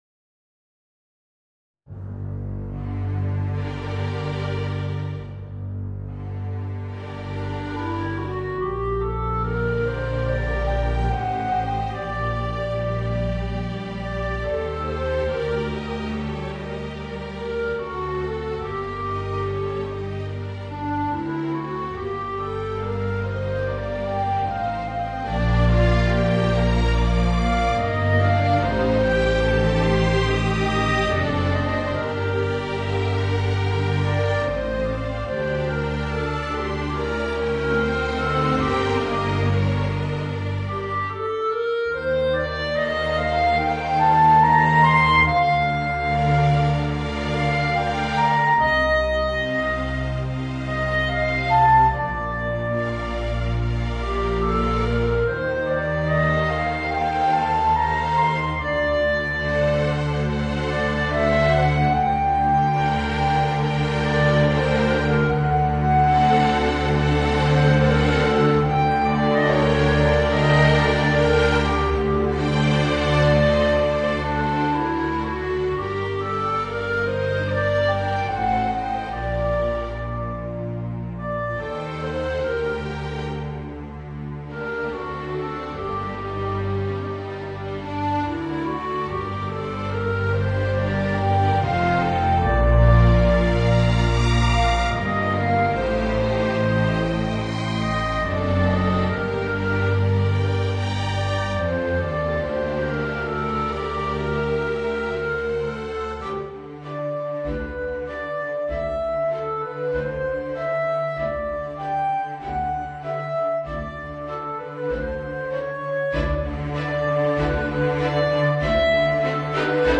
Voicing: Tenor Saxophone and String Orchestra